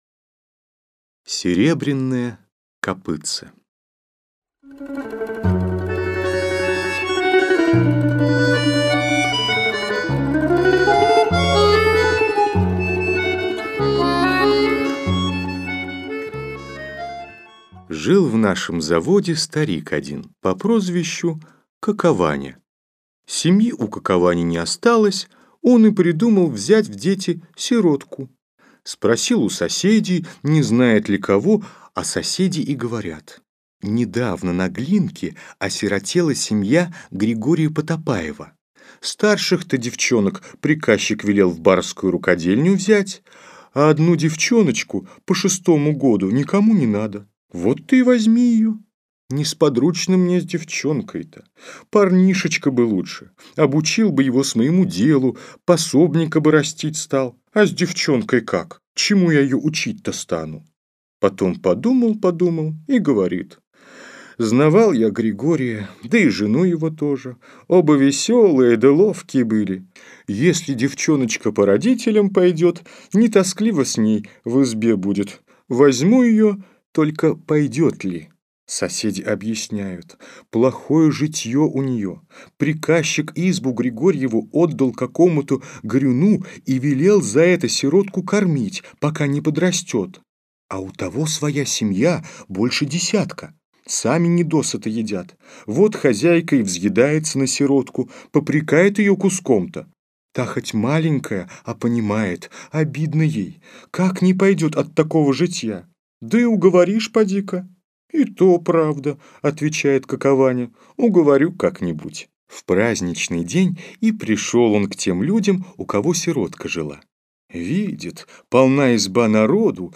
Аудиокнига Сказы | Библиотека аудиокниг